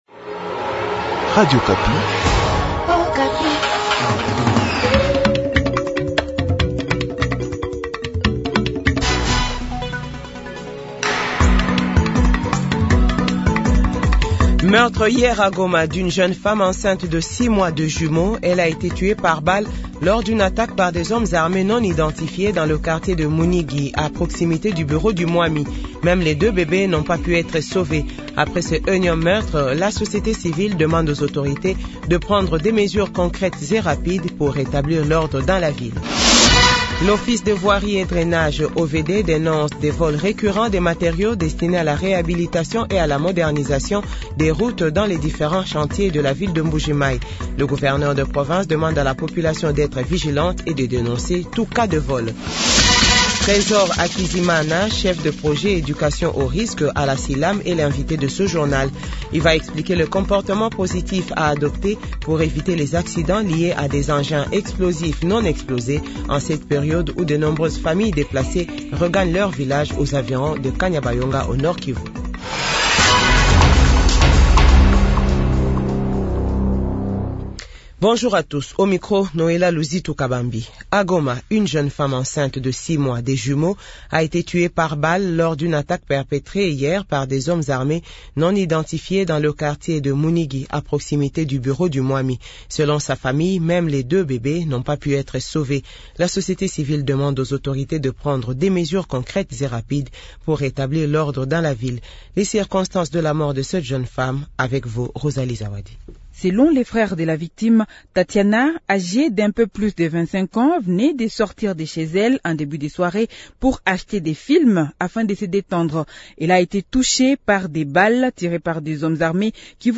JOURNAL FRANCAIS DE 12H00